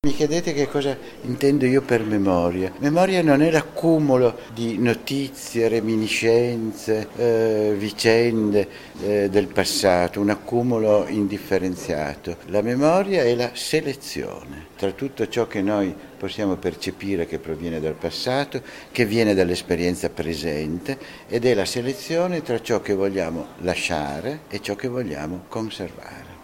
Lo stiamo scoprendo in questi giorni intervistando alcuni degli ospiti della seconda edizione del Memoria Festival di Mirandola:
il giurista Gustavo Zagrebelsky